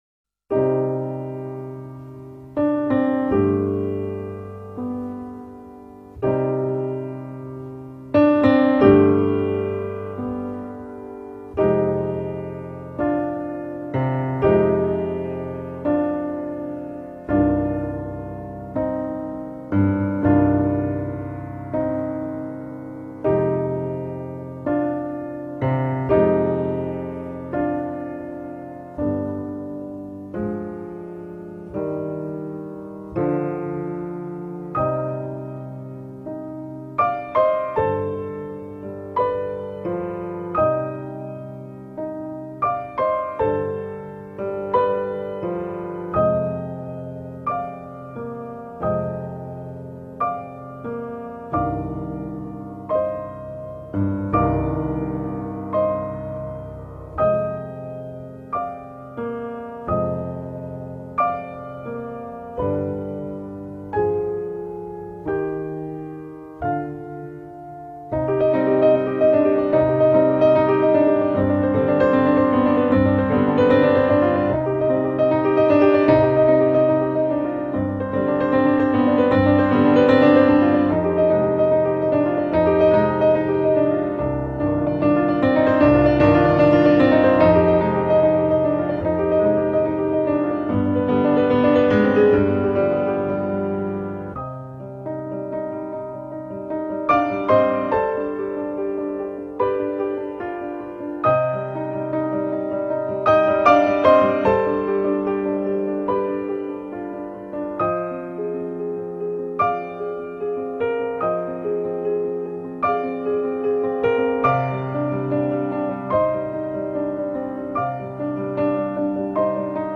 Beautiful Soft Piano Music (открыта)